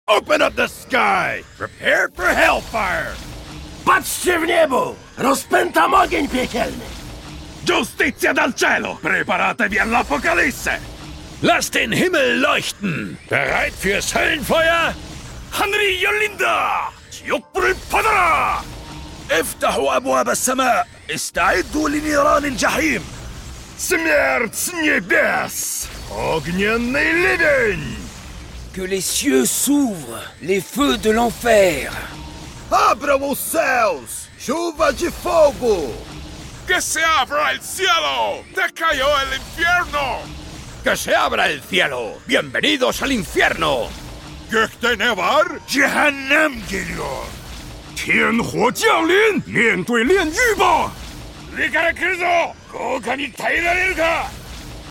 All Brimstone Ultimate Voice Lines sound effects free download
All Brimstone Ultimate Voice Lines in Every Language | Valorant